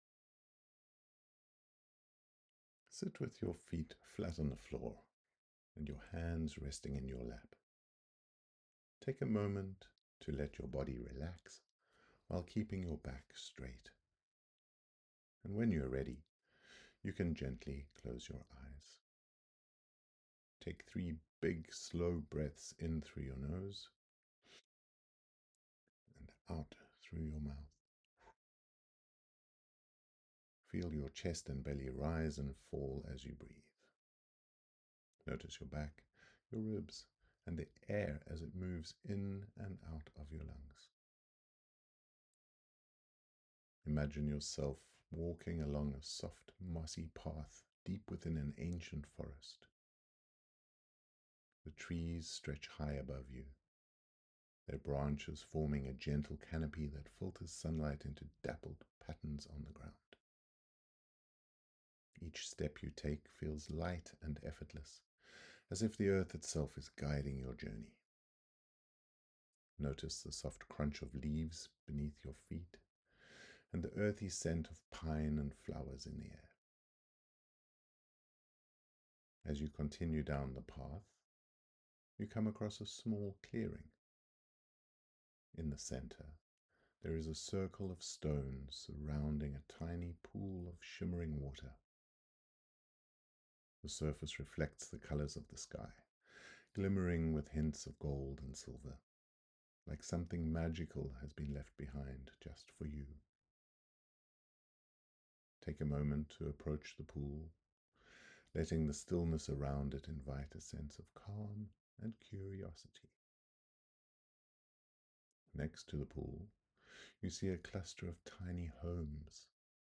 EnchanTed Garden Meditation
TP01-meditation.mp3